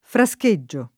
frascheggio [ fra S ke JJ& o ] s. m.